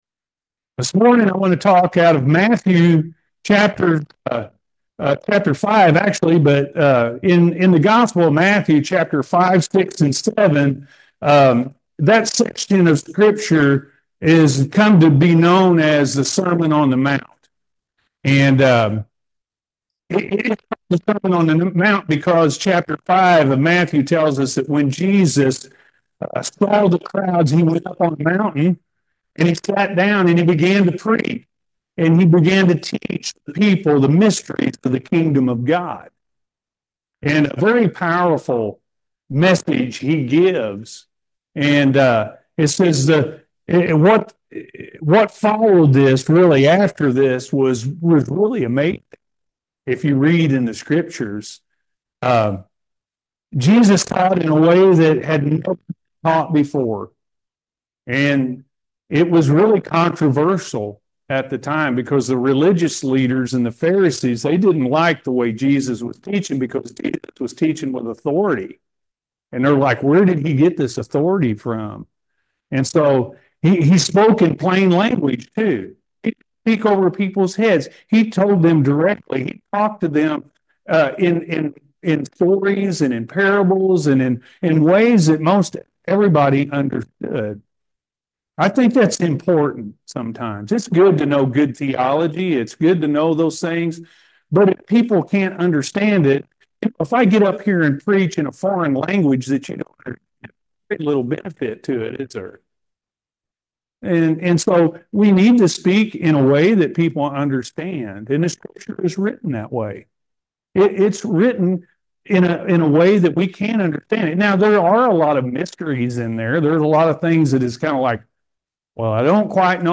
The Beatitudes-A.M. Service